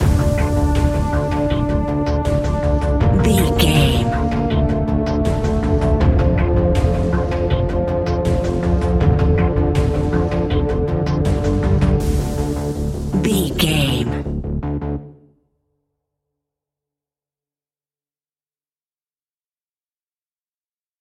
Aeolian/Minor
D
ominous
dark
haunting
eerie
synthesiser
strings
drums
percussion
horror music